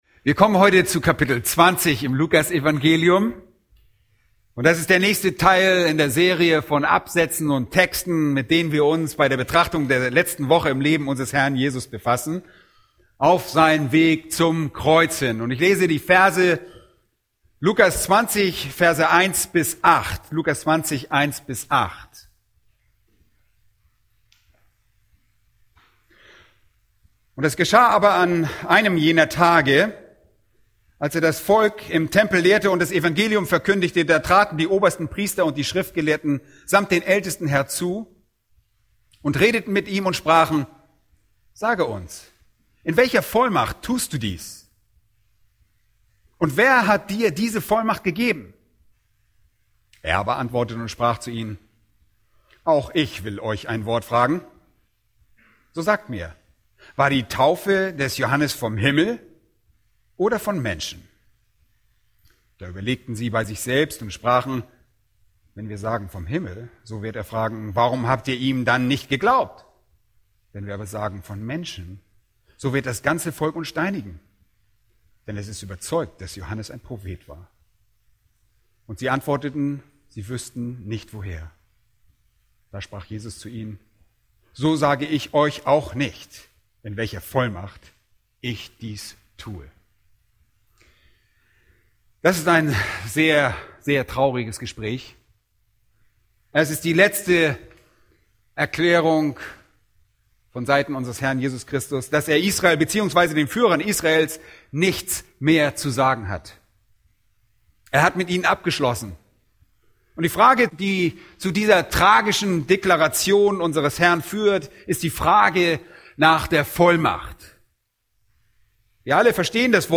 Aus der Serie: Wie man mit Häretikern spricht* | Weitere Predigten